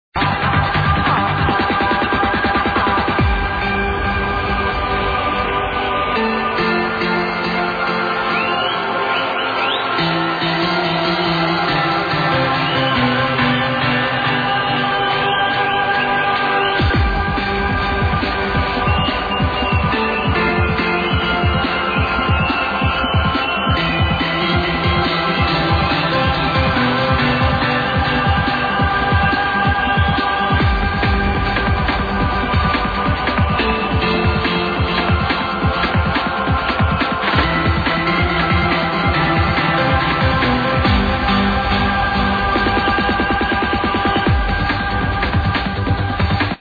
trancey breaks tracks